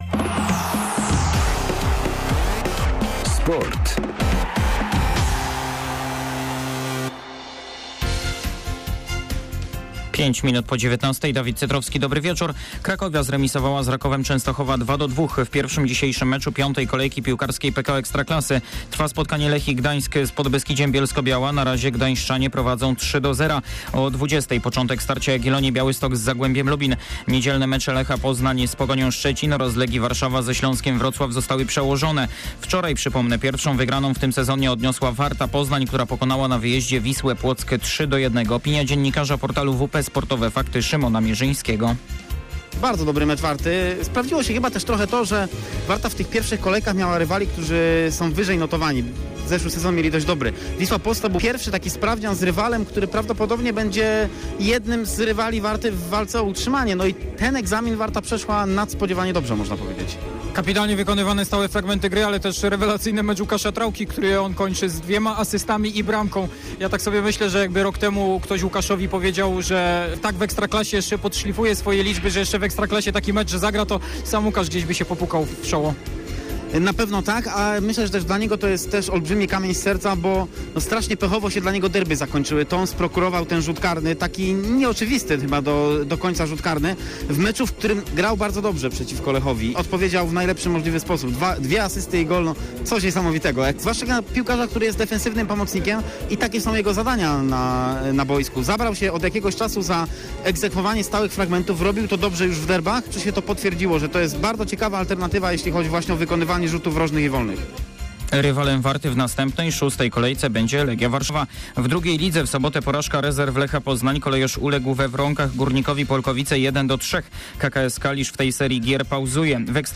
26.09. SERWIS SPORTOWY GODZ. 19:05